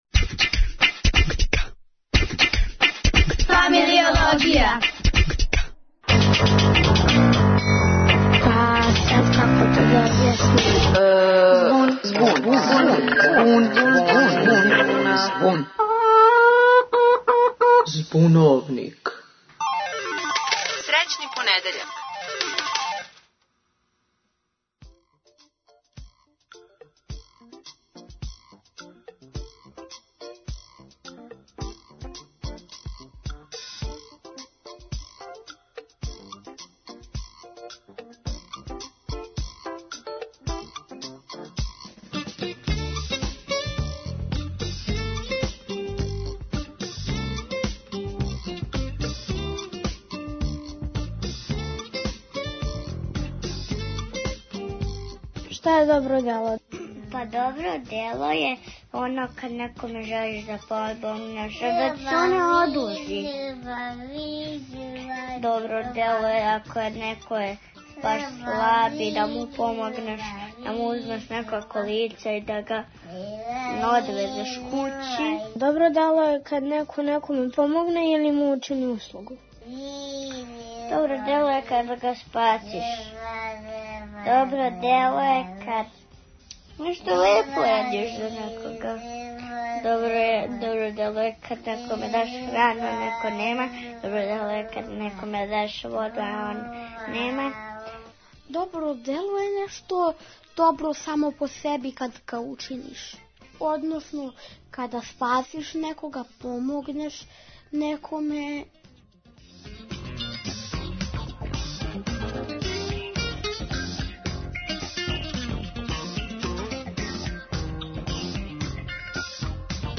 О добричинству и потреби чињења добрих дела у данашњем Збуновнику говоре деца, млади и